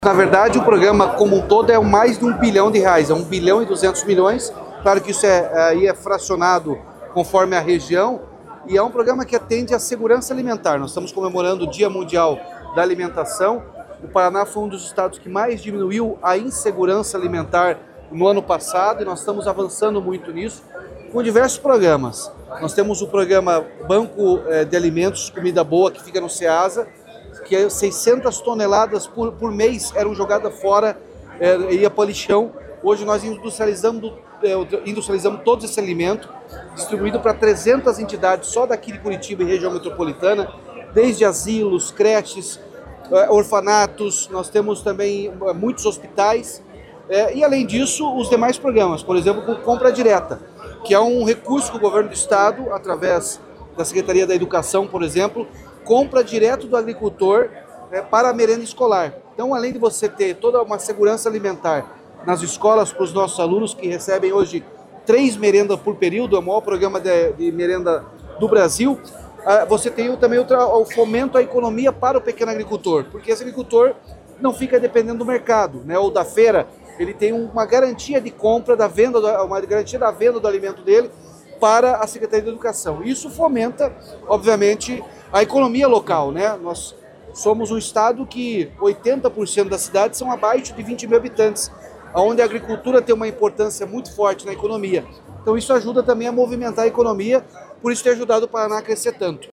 Sonora do governador Ratinho Junior sobre os programas do Estado que garantem segurança alimentar